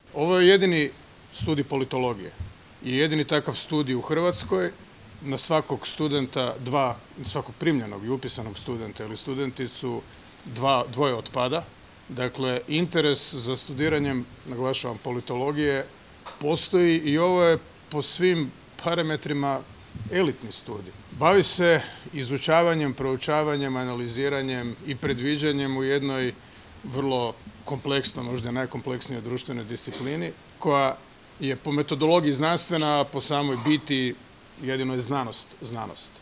Koliko je politologija važna u vremenu u kojemu živimo, svjestan je i predsjednik Zoran Milanović, koji se na svečanosti obilježavanja Dana Fakulteta političkih znanosti osvrnuo na trenutnu aktualnu situaciju u zemlji i svijetu.